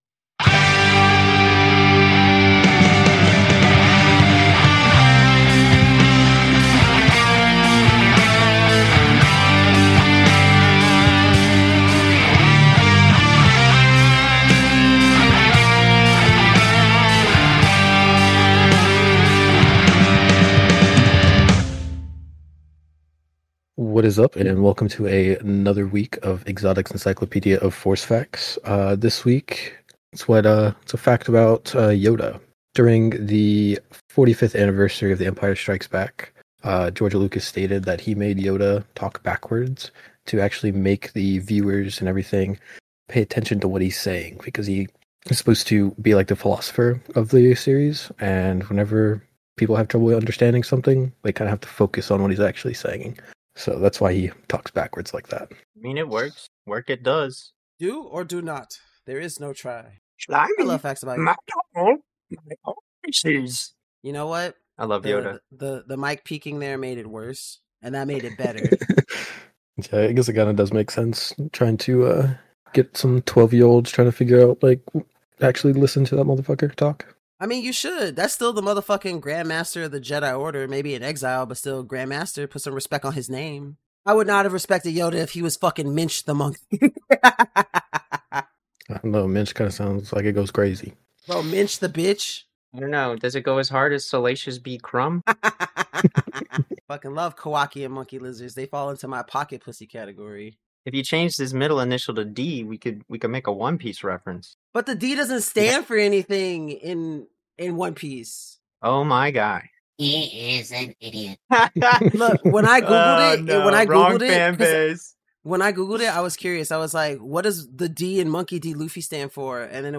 Welcome to The Force Unscripted Podcast, where Star Wars fans share unfiltered discussions, fresh insights, and genuine camaraderie in exploring the galaxy far, far away.